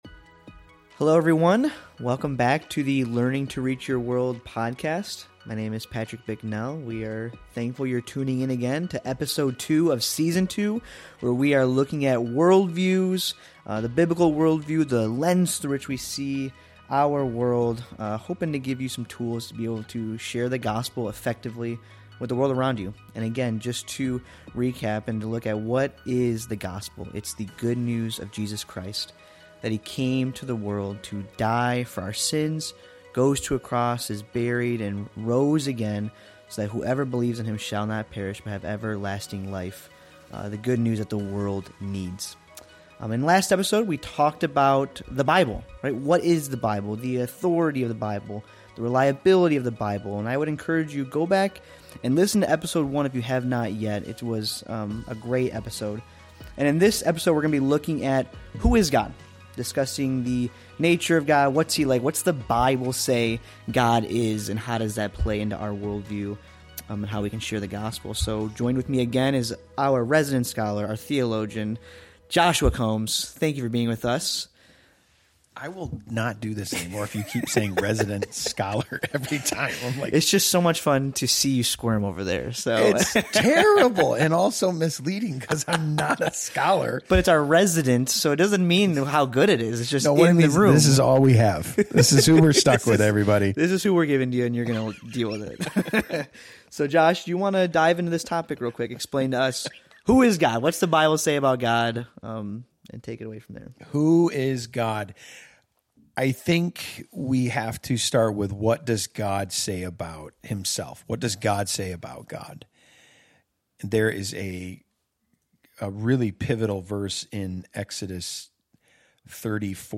In Episode 2 of Season 2 of the Learning to Reach Your World podcast, the conversation continues around developing a biblical worldview—the lens through which Christians see reality—in order to more effectively share the gospel. After briefly restating the gospel as the good news of Jesus Christ’s death, burial, and resurrection for eternal life, the hosts turn their attention to a foundational question: Who is God?